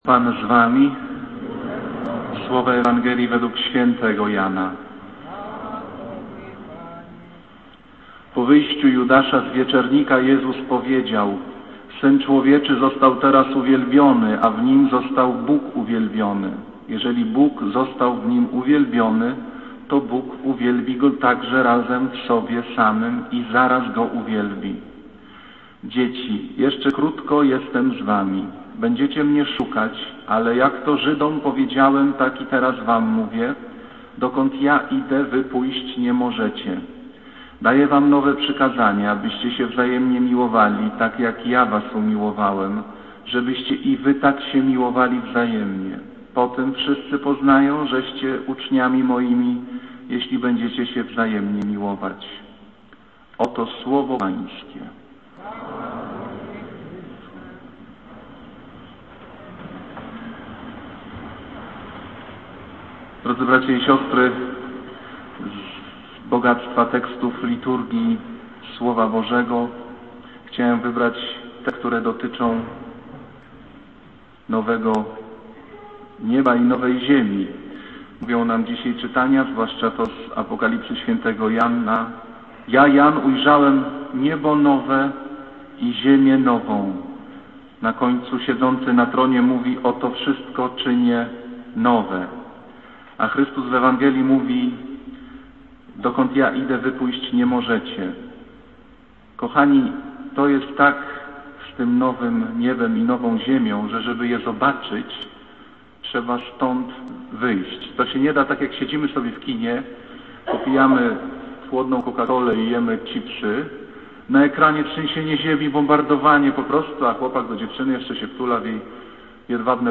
Kazanie z 5 maja 2007r.
niedziela, godzina 15:00, kościół św. Anny w Warszawie